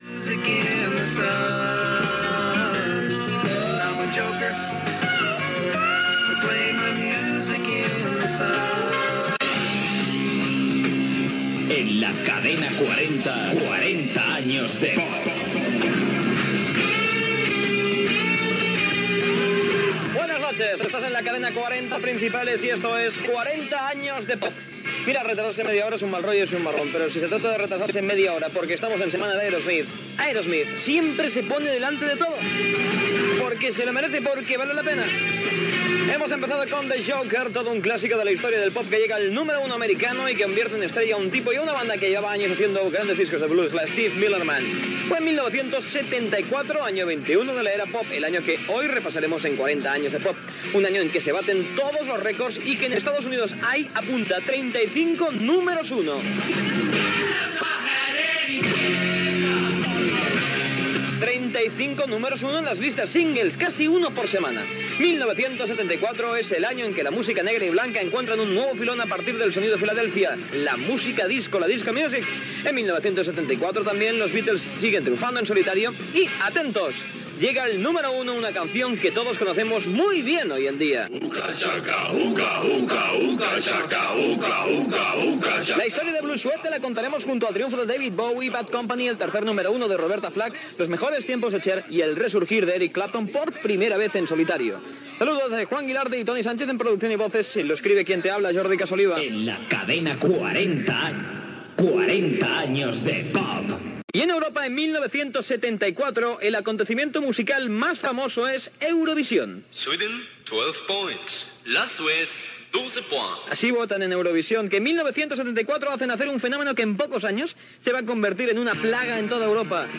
Tema musical, careta del programa, els èxits de l'any 1974, equip, indicatiu, el Festival d'Eurovisió a l'any 1974 i tema musical
Musical
FM